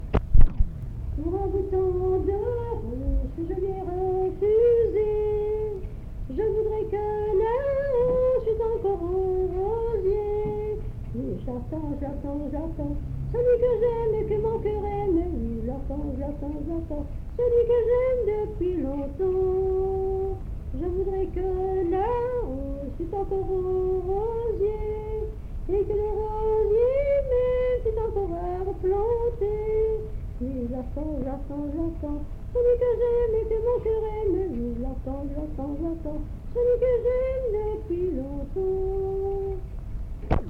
Découvrez notre collection d'enregistrements de musique traditionnelle de Wallonie
Type : cramignon Aire culturelle d'origine : Bassin liégeois Interprète(s